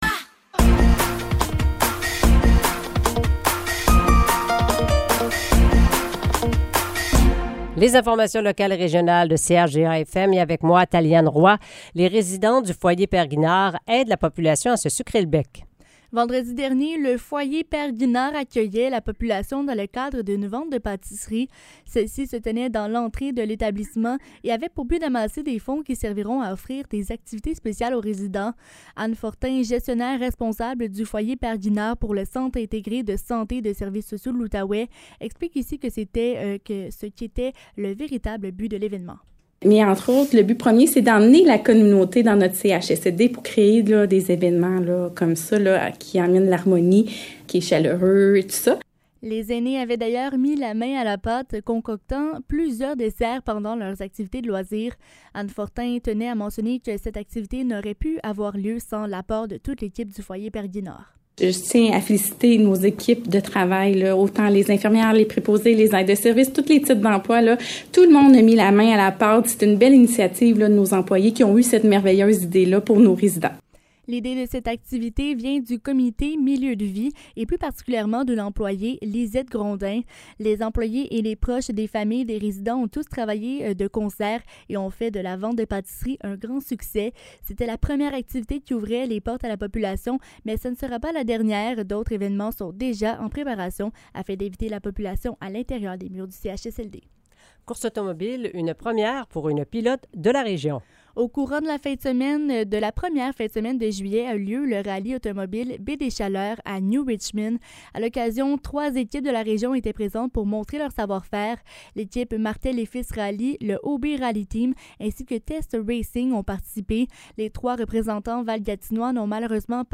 Nouvelles locales - 10 juillet 2023 - 16 h